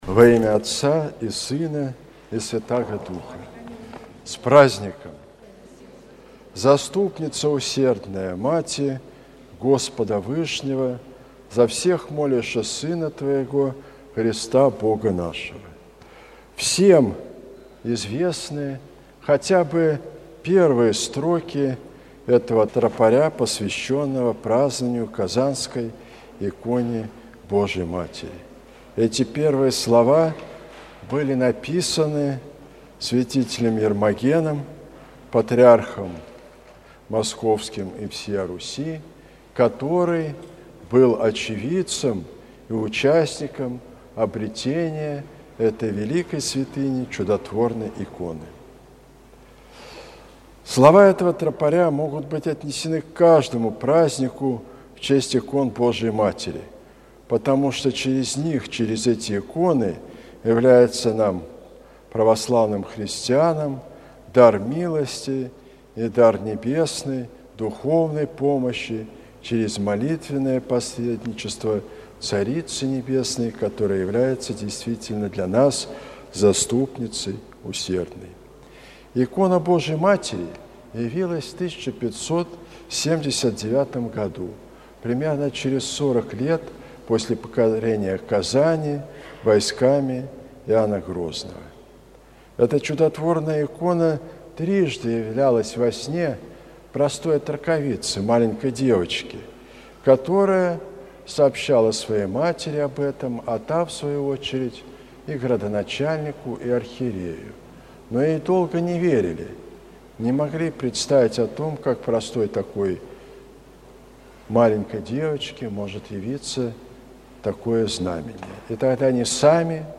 Слово в день празднования Казанской иконы Божией Матери